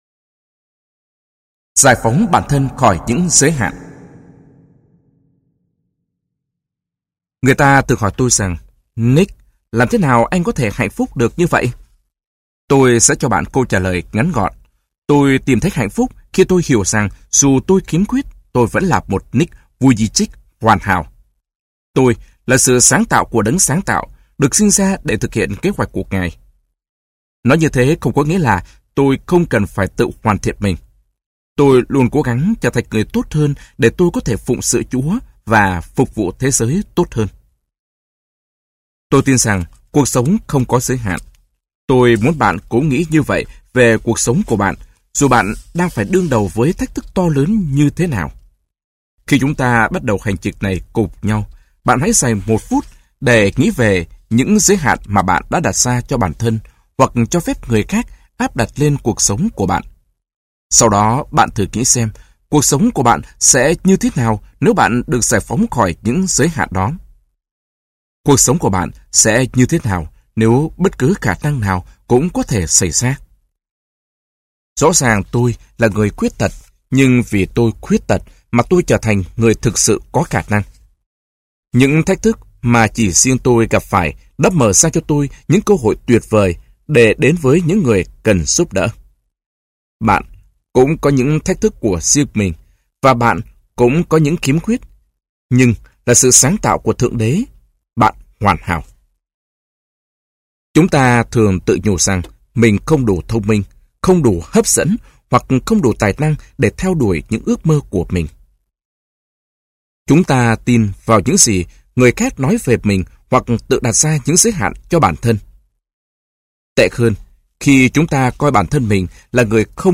Sách nói Sống Cho Điều Ý Nghĩa Hơn - Nick Vujicic - Sách Nói Online Hay